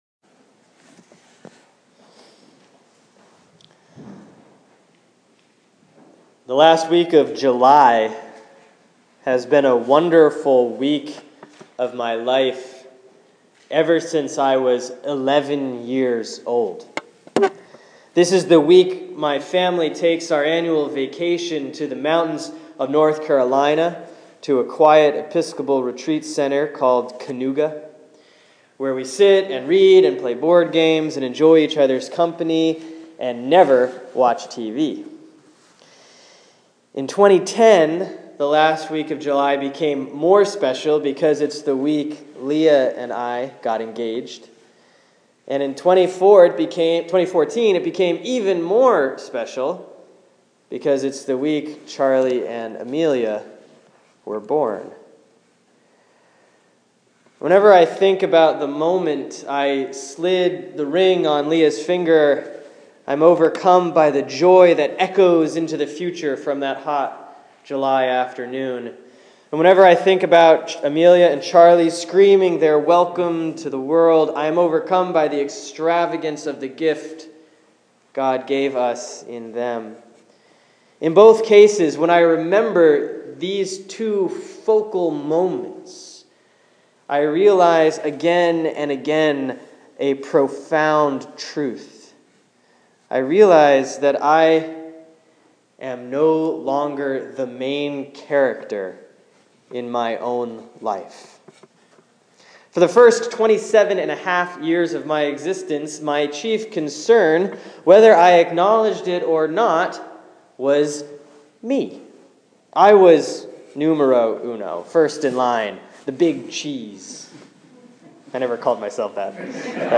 Sermon for Sunday, March 1, 2015 || Lent 2B || Mark 8:31-38